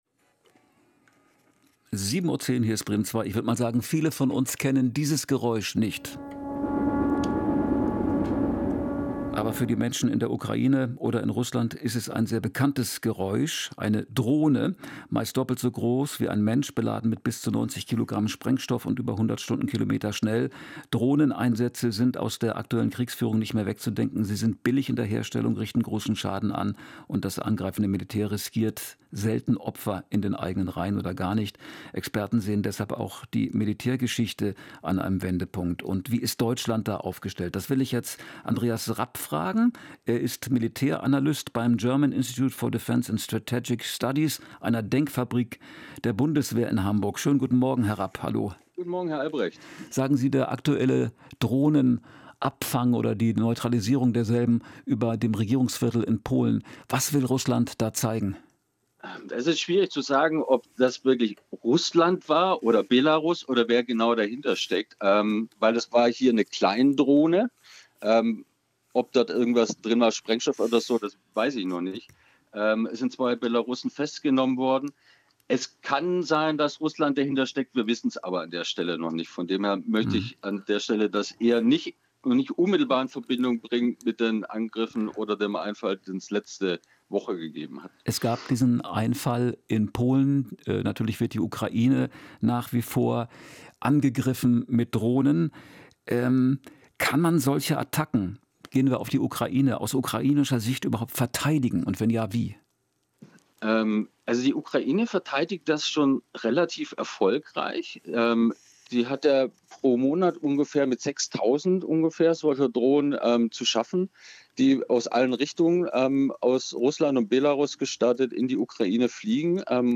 Radio Bremen 2, „Der Morgen. Informationen und Hintergründe aus Politik, Kultur und Gesellschaft“, Interview